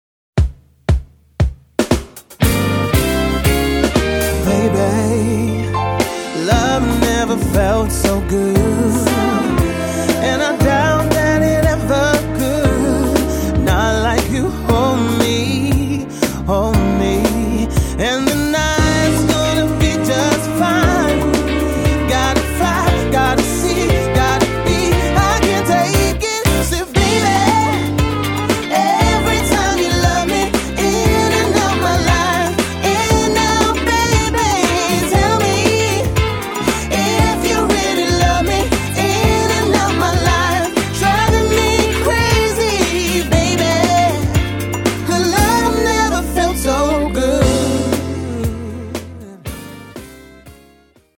8-piece Party Band